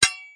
metal2.mp3